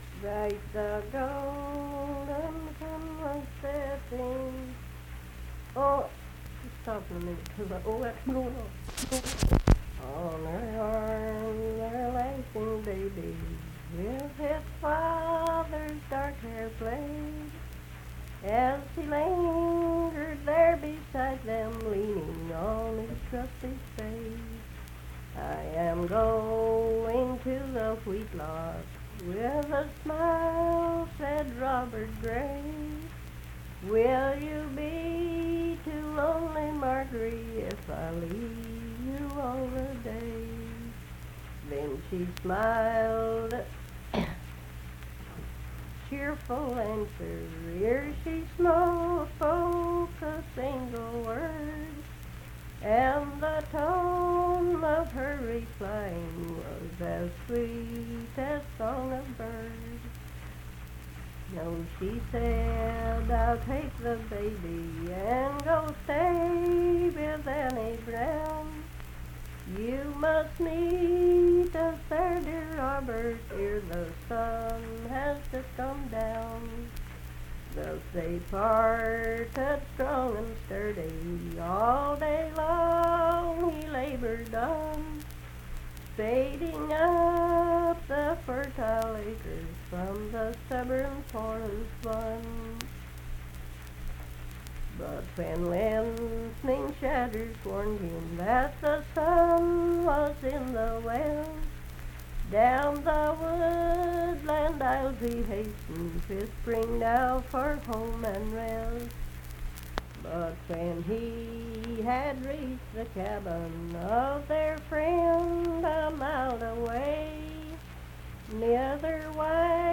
Legend Of Vermont - West Virginia Folk Music | WVU Libraries
Unaccompanied vocal music performance
Verse-refrain 31(4).
Voice (sung)